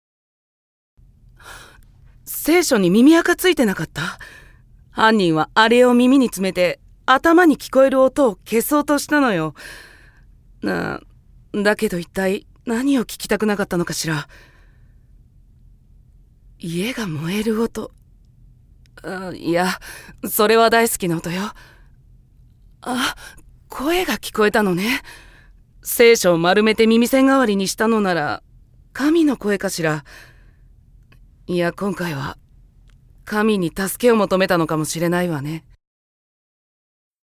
◆外画　女性捜査官◆